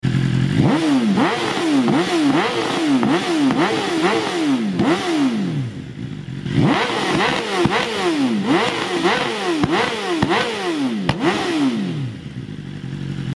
Akraprovic Full system S1000rr💥🔥